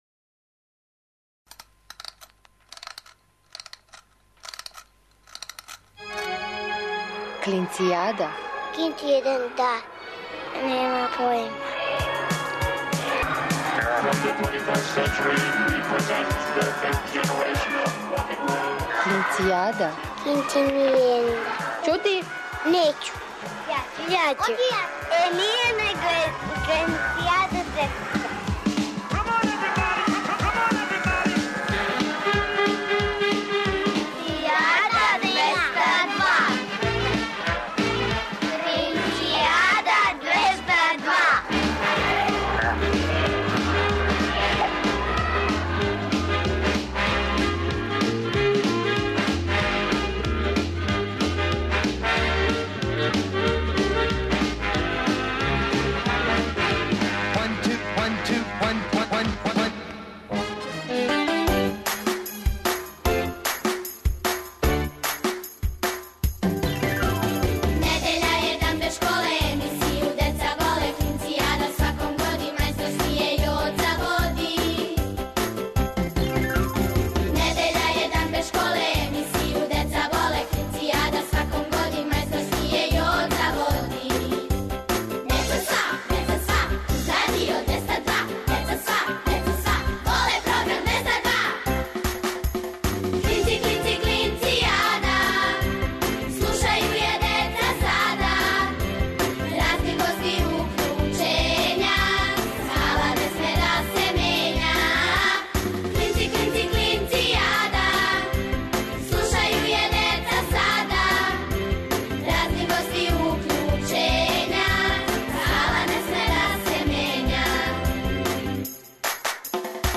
О деци за децу, емисија за клинце и клинцезе, и све оне који су у души остали деца. Сваке недеље уживајте у великим причама малих људи, бајкама, дечјим песмицама.